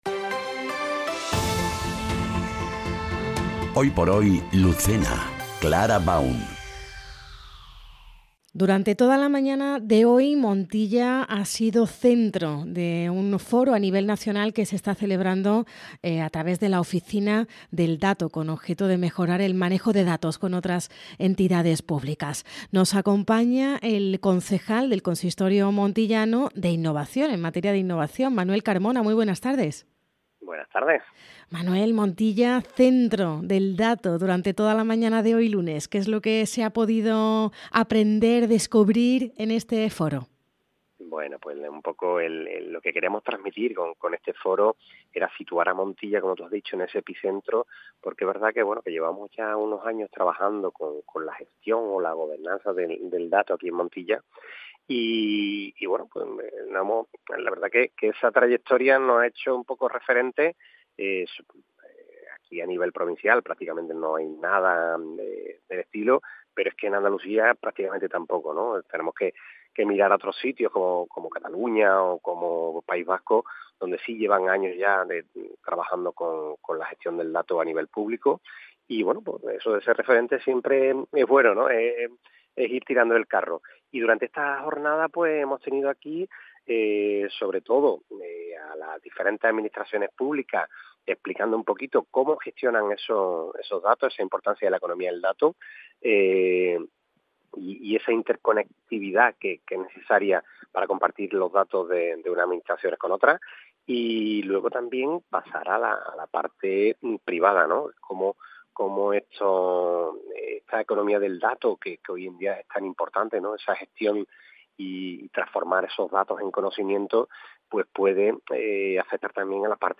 Entrevista Manuel Carmona | Foro del Dato Montilla
En Hoy por Hoy Andalucía Centro Lucena entrevistamos a Manuel Carmona, edil de Innovación de Montilla, para hablar del Foro Nacional del Dato que ha acogido el municipio esta mañana con objeto de mejorar el manejo de datos con otras entidades públicas, además de trabajar con estos datos para mejorar decisiones políticas y traducirlas a información y conocimiento.